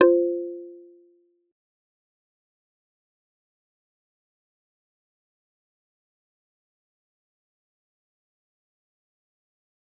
G_Kalimba-F4-mf.wav